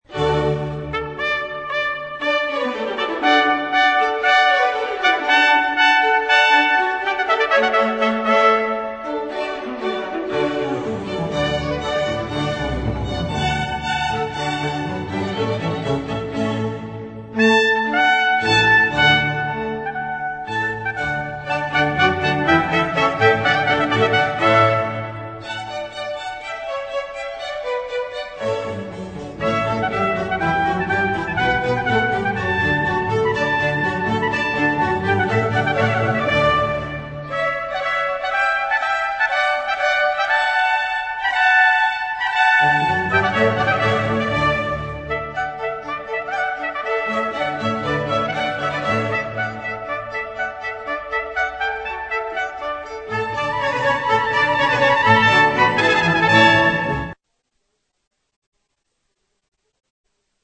Trompette